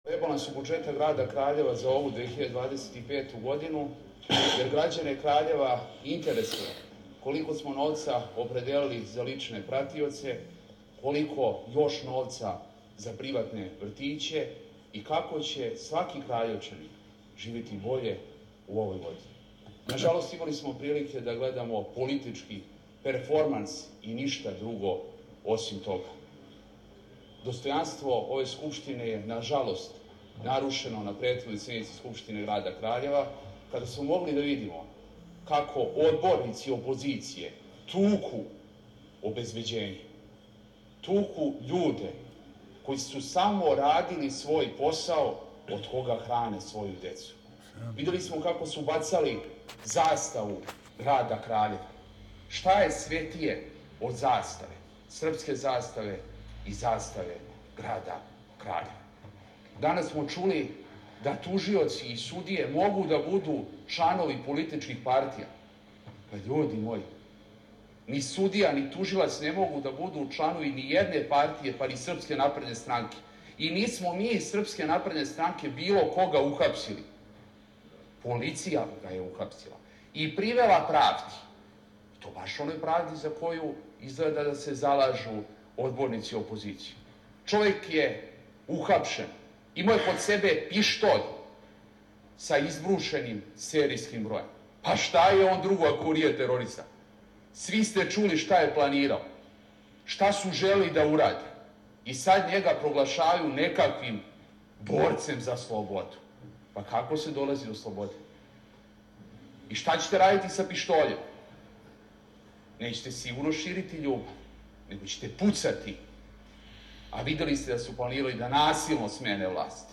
dr. Prerag Terzić,gradonačelnik Kraljeva
Potom su odbornici opozicije napustili skupštinsku salu a njima su ,u odsustvu, replicirali odbornica Jedinstvene Srbije Marija Jevđić, šef odborničke grupe SNS Dragiša Radević i gradonačelnik Predrag Terzić  a to šta je on tom prilikom rekao možete čuti